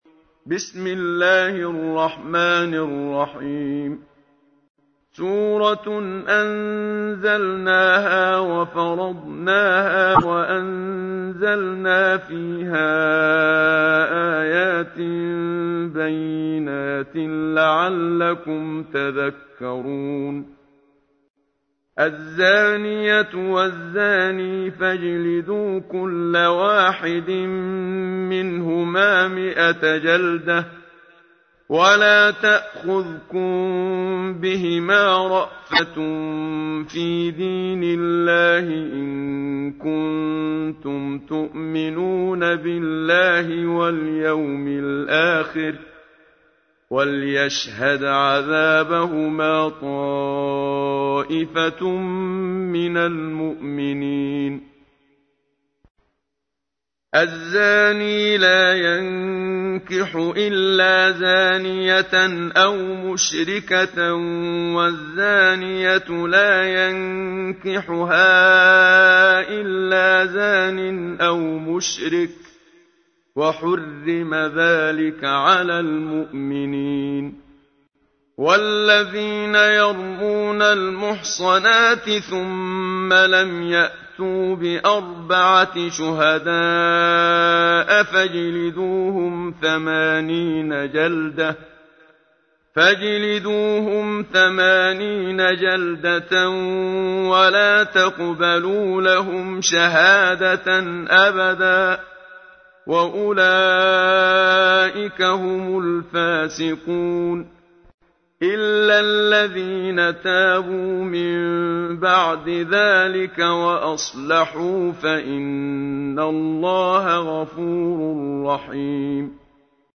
همه چیز در مورد سوره مبارکه نور+متن و ترجمه+ تلاوت ترتیل استاد منشاوی